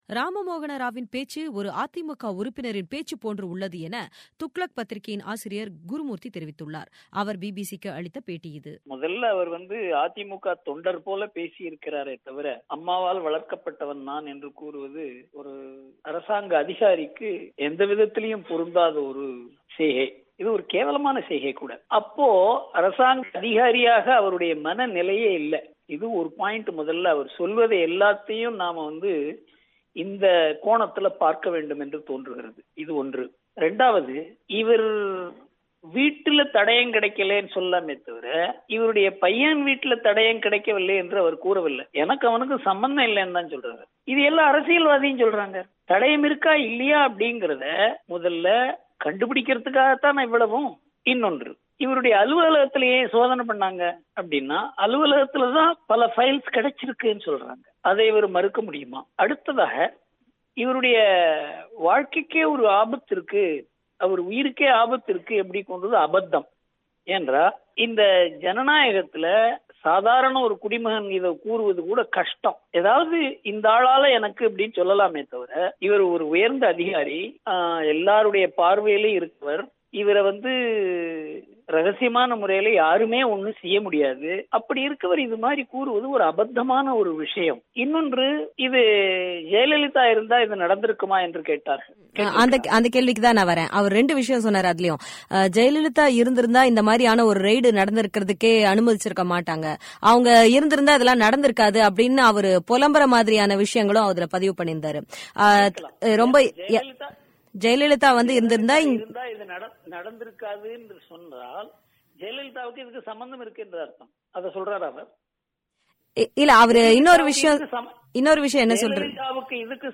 அதிமுக உறுப்பினர் போல் பேசுகிறார் ராம மோகன ராவ் : குருமூர்த்தி பேட்டி